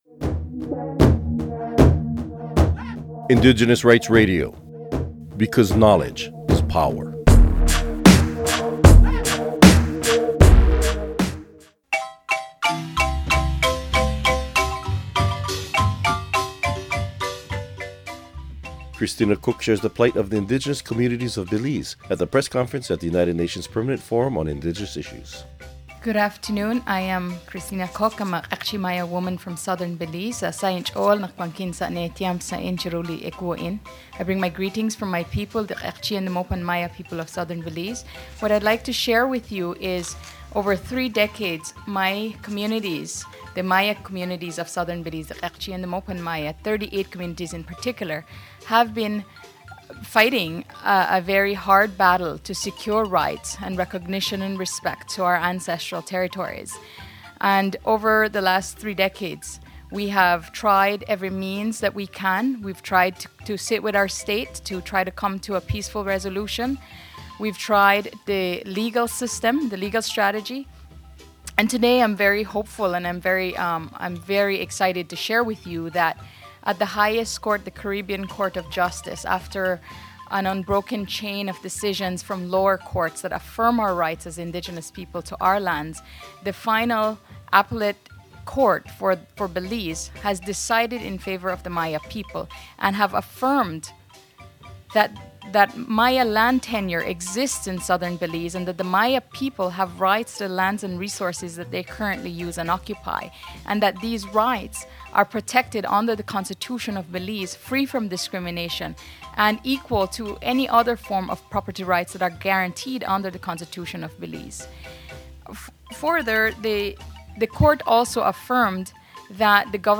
Recording Location: UNPFII 2015
Type: Interview
0kbps Stereo